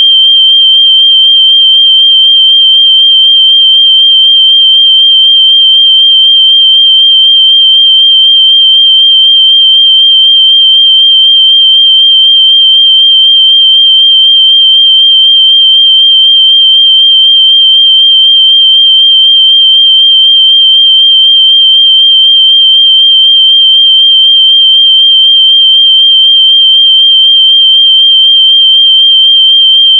下面是用Multi-Instrument的信号发生器生成的30秒长的标准测试信号（WAV文件），可供下载。